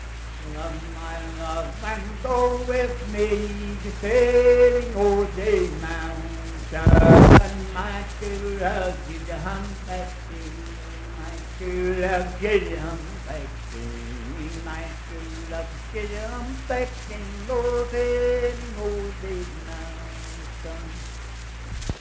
Unaccompanied vocal music performance
Dance, Game, and Party Songs
Voice (sung)